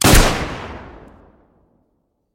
shot4.mp3